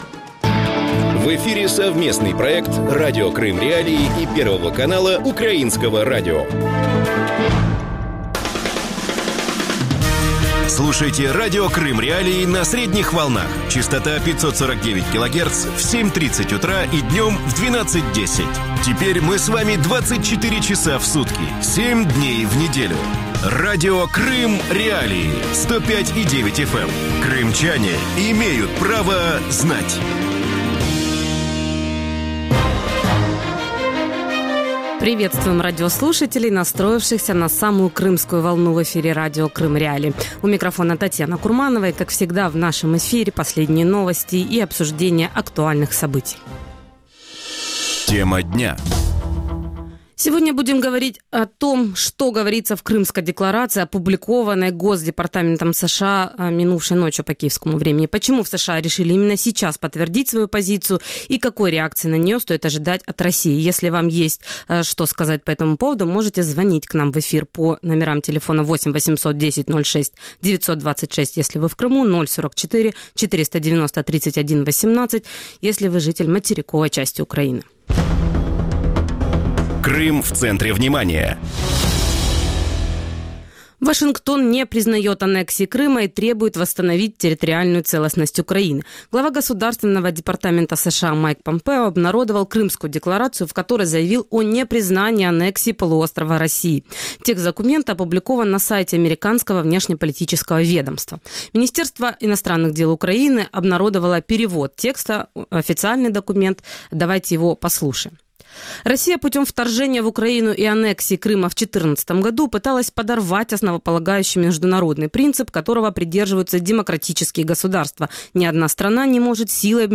украинский политолог-международник